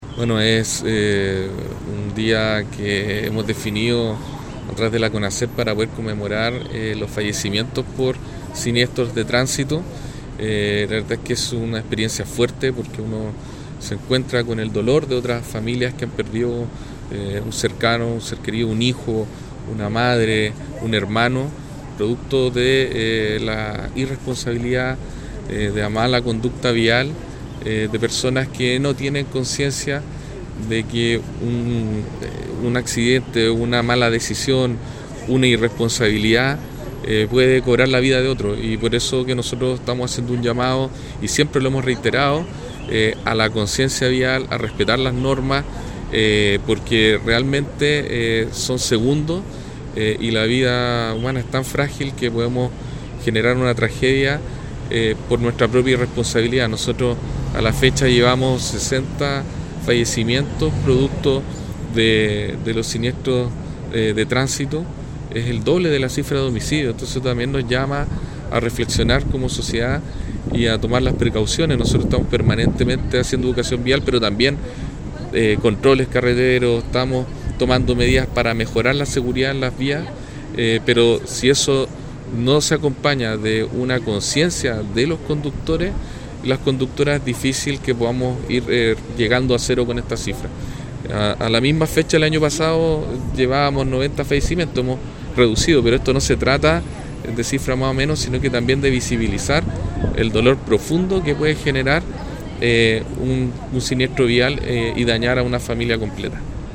Para el Delegado Presidencial, Galo Luna Penna,
ACCIDENTES-VIALES-Delegado-Presidencial-Galo-Luna-Penna.mp3